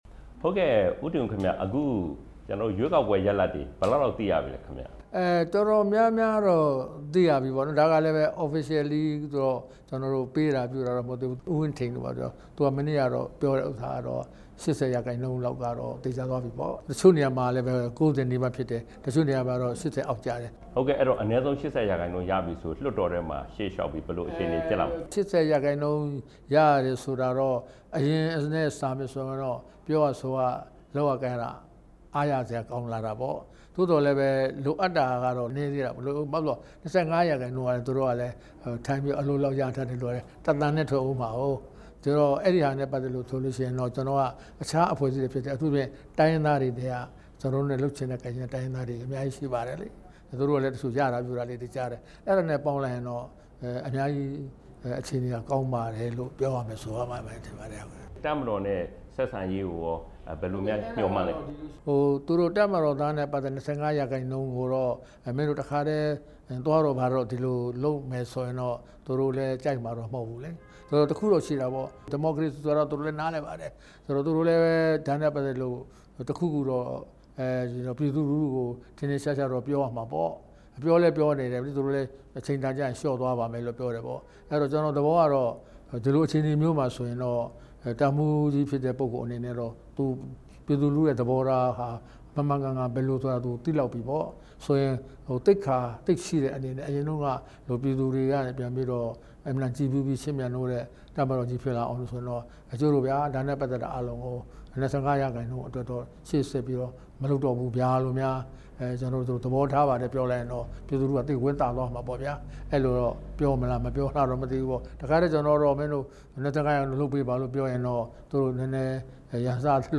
NLD နာယက ဦးတင်ဦးနဲ့ တေ့ွဆုံမေးမြန်းချက်